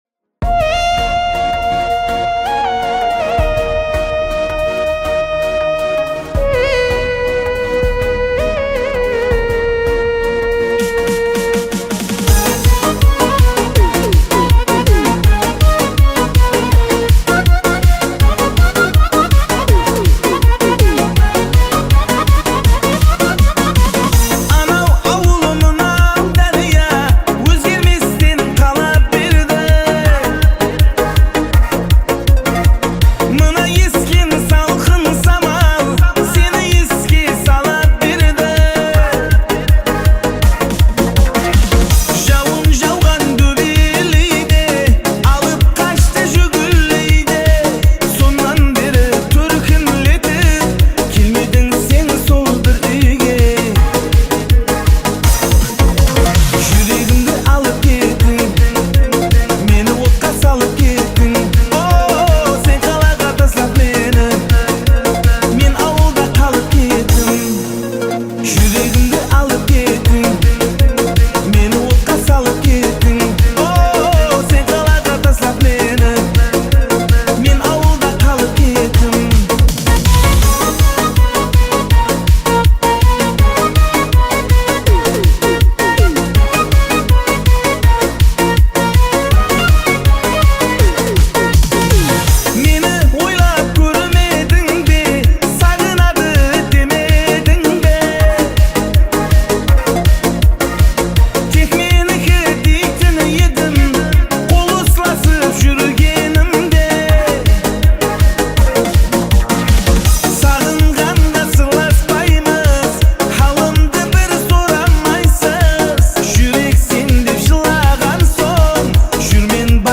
Качество: 320 kbps, stereo
Узбекская музыка